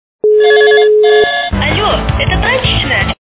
» Звуки » Смешные » Але! Это прачечная? - Женский голос
При прослушивании Але! Это прачечная? - Женский голос качество понижено и присутствуют гудки.